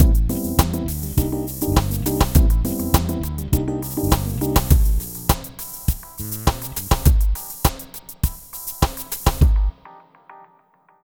Ala Brzl 1 Fnky Full-A.wav